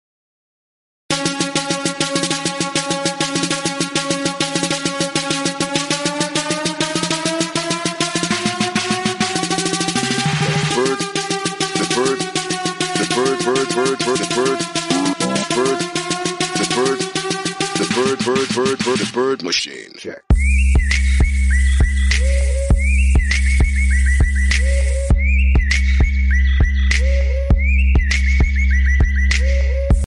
Kategori Hayvan